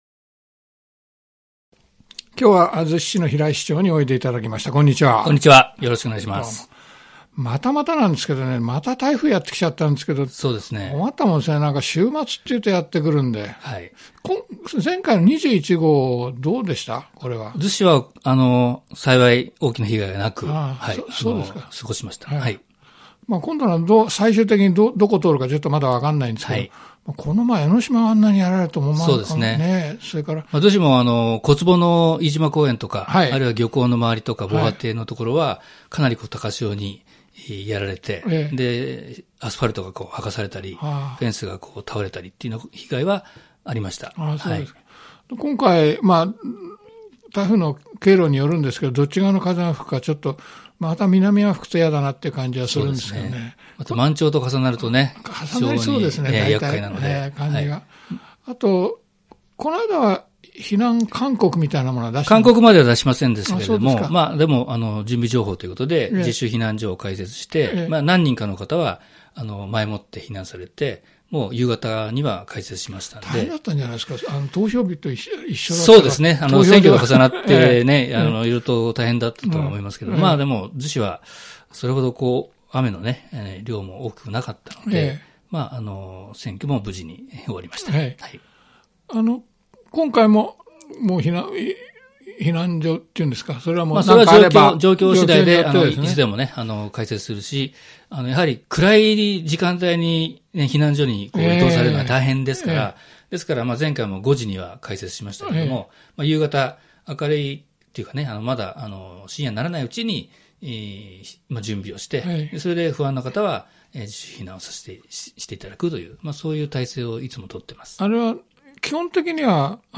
逗子市長・平井竜一さんに木村太郎（湘南ビーチＦＭ代表取締役）がインタビューを行いました。